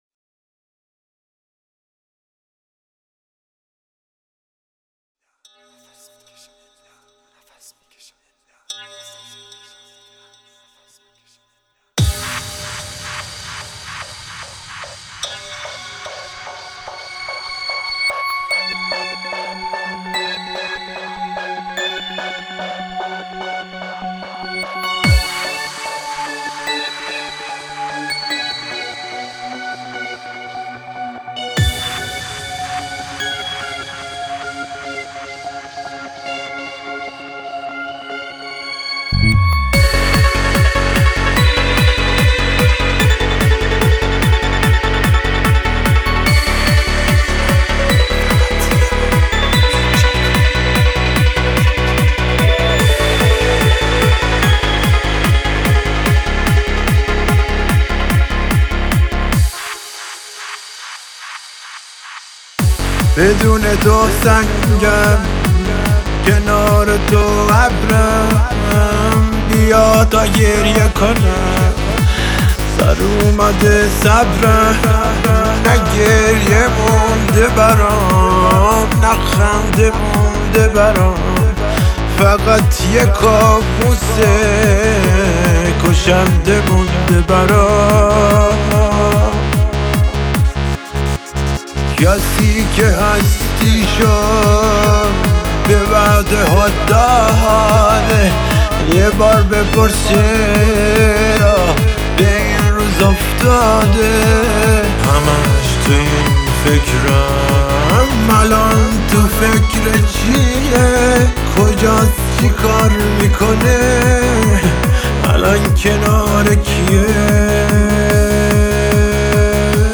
آهنگ احساسی و متفاوت